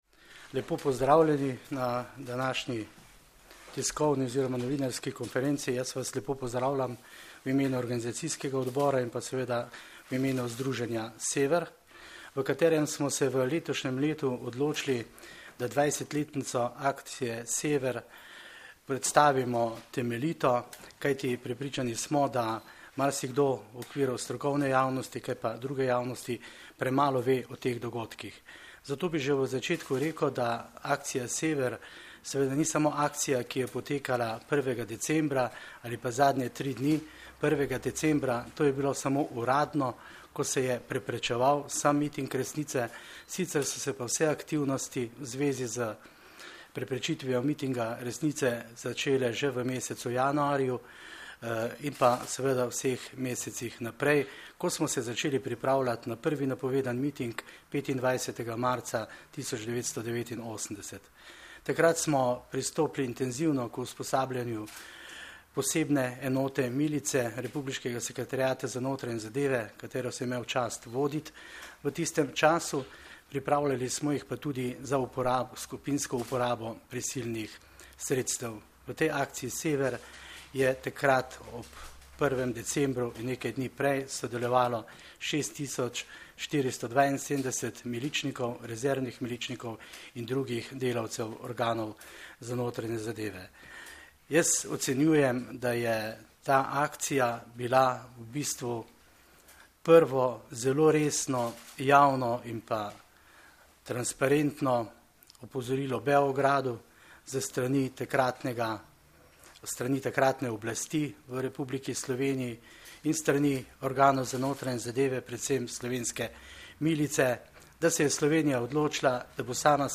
Predstavniki Združenja Sever so na današnji novinarski konferenci podrobneje predstavili aktivnosti, s katerimi bomo letos obeležili 20. obletnico akcije Sever.